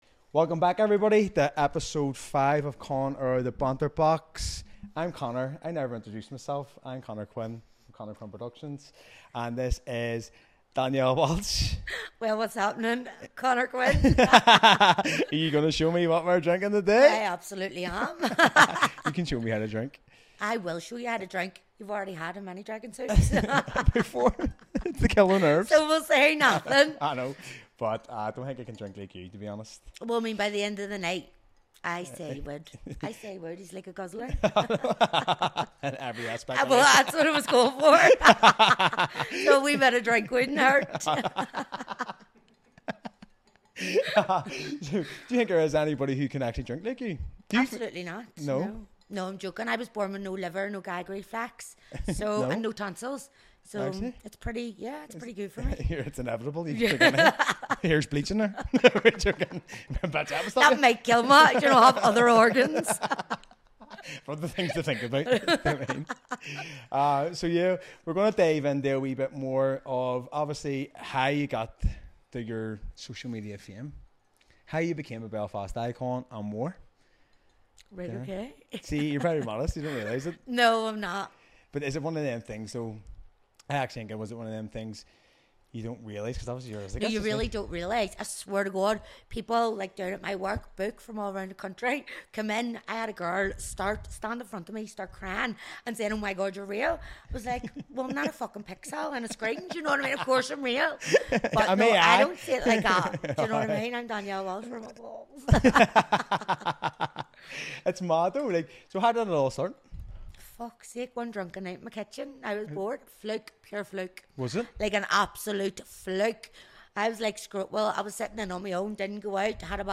We'll talk about her journey, the opportunities social media brings, and, of course, her favorite drinks. 🍹 Expect plenty of laughs, banter, and maybe a few messy moments—it's all part of the fun!